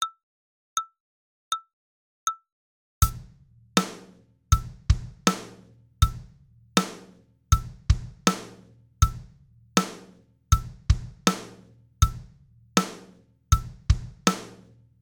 ４ビート
右手のハイハットを8回のうち奇数のところだけ叩きます。（１・３・５・７・）
８ビートでは１小節に８回ハイハットが入りましたが、４ビートはその４回バージョンになります。（スネア・バスドラムは８ビートと一緒です。）
基本ビートは全てBPM80となっていますが、初めは60くらいに落として、叩けるようになってきたら速度を上げていきます。
4ビート1.mp3